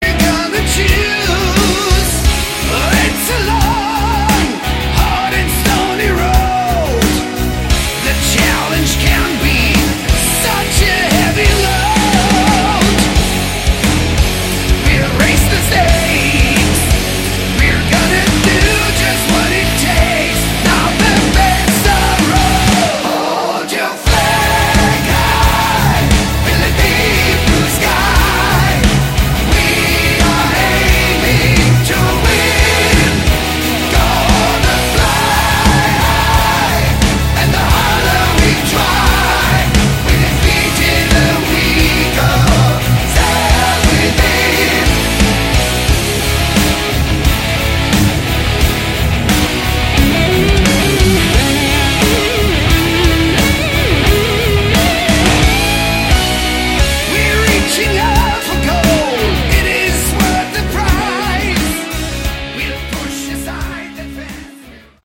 Category: Hard Rock
This time more of a power metal feel.